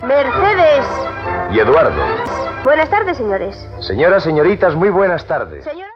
Careta i salutació inicial.
Entreteniment